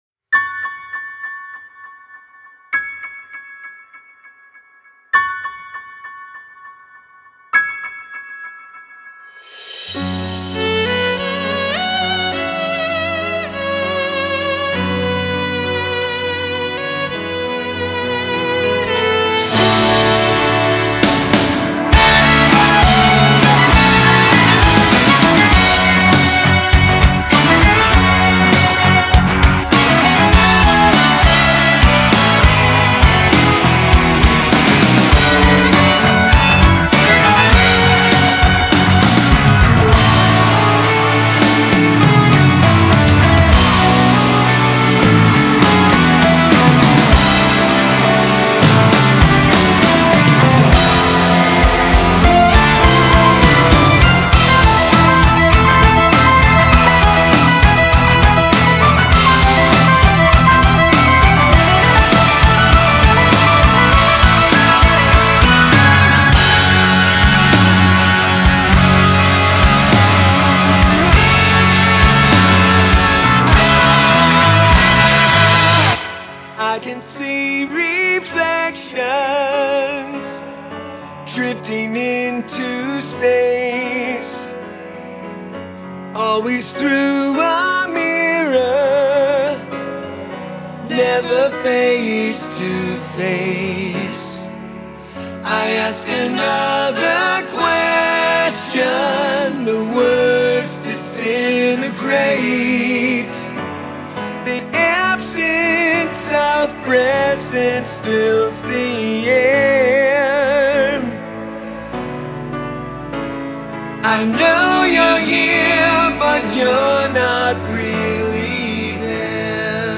Progressive Rock / Hard Rock